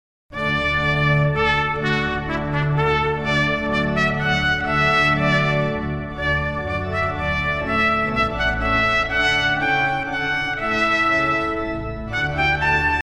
Minuet Finale, Royal Fireworks-Handel (organ & trumpet)